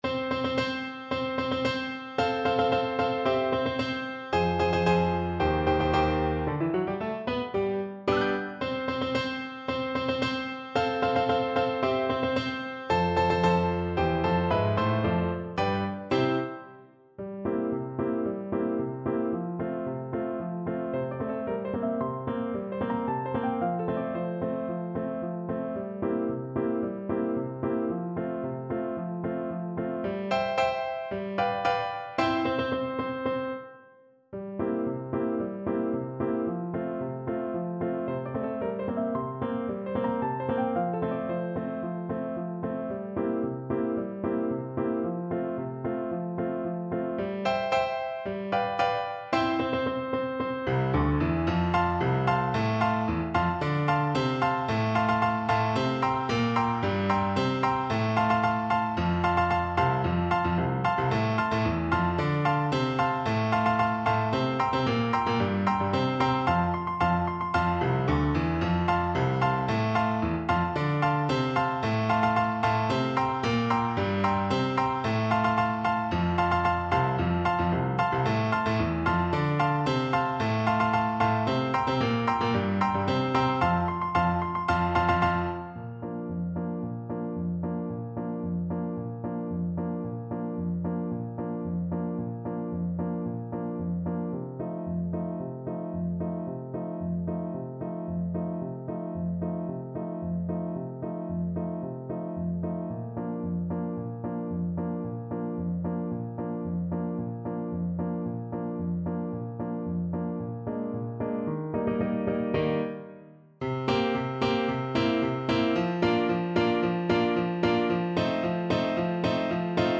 Play (or use space bar on your keyboard) Pause Music Playalong - Piano Accompaniment Playalong Band Accompaniment not yet available transpose reset tempo print settings full screen
2/4 (View more 2/4 Music)
F major (Sounding Pitch) G major (Clarinet in Bb) (View more F major Music for Clarinet )
~ = 112 Introduction
Classical (View more Classical Clarinet Music)